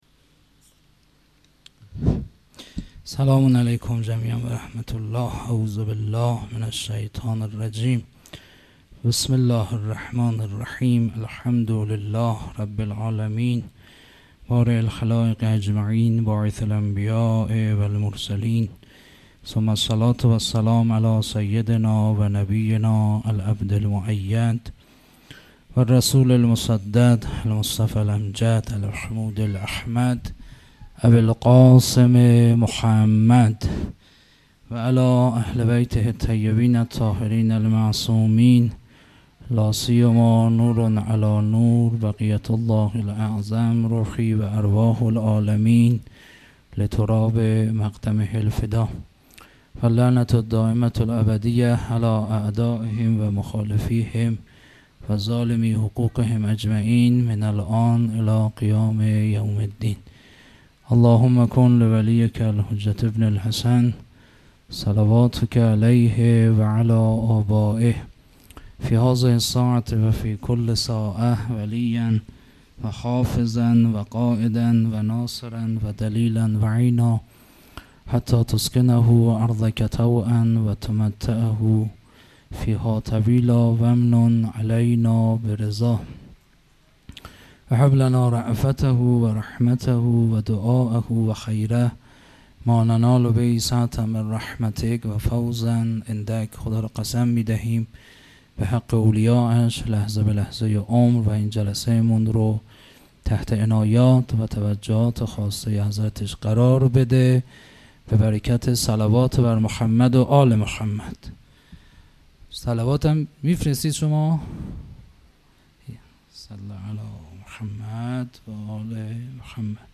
0 0 سخنران